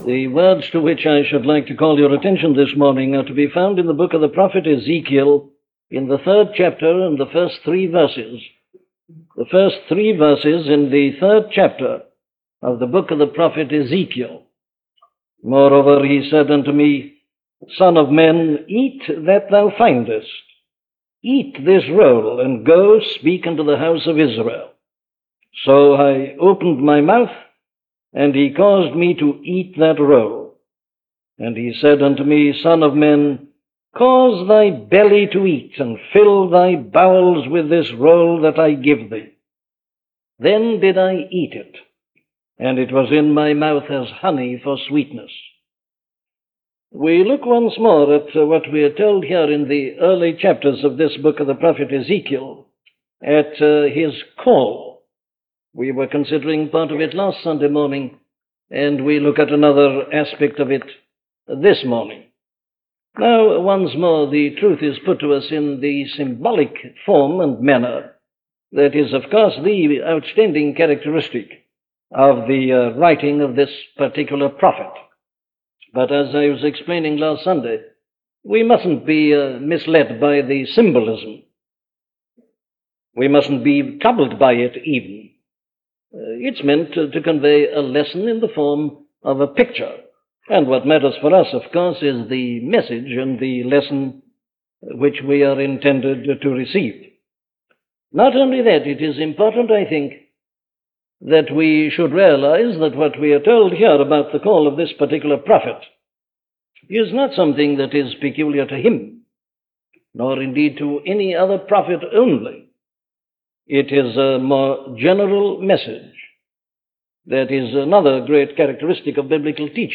Dr. Martyn Lloyd-Jones's sermons on the Book of Ezekiel were delivered at Westminster Chapel in London as part of his wider exposition of Old Testament prophetic literature.